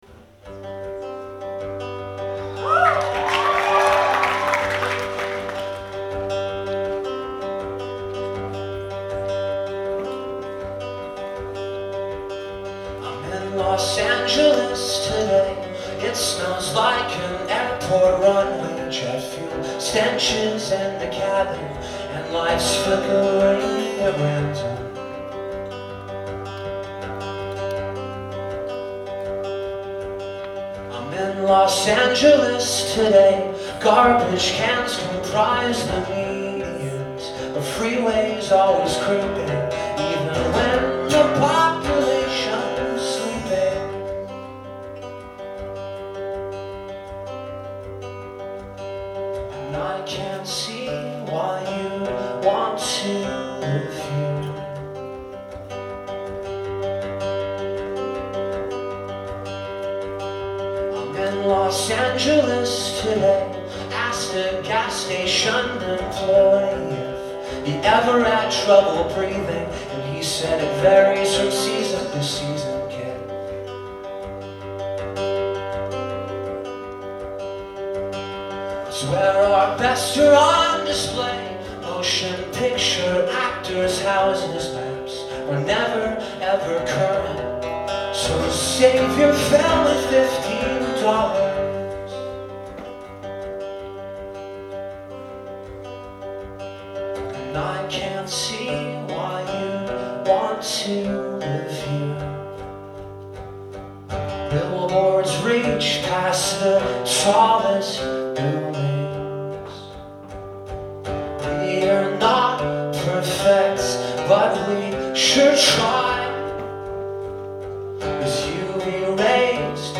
Live at the Somerville Theatre
in Somerville, Massachusetts